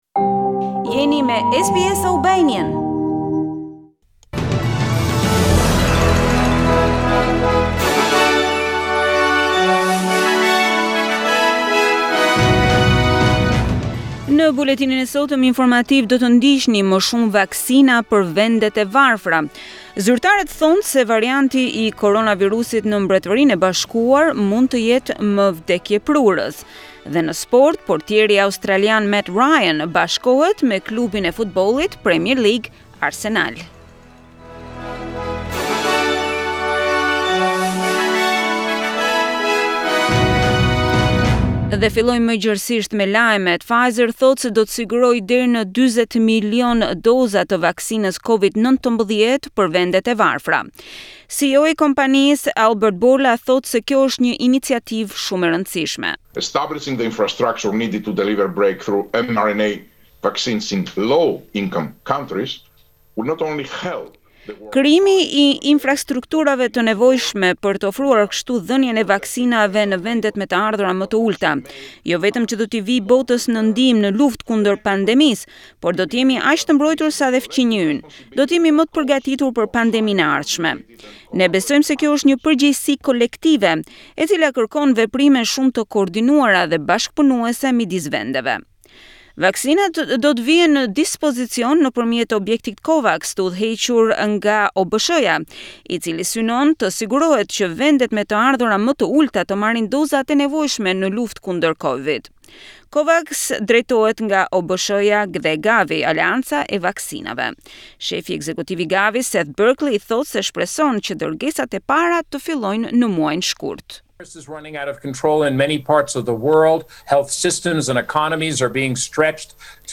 SBS News Bulletin in Albanian - 23 January 2021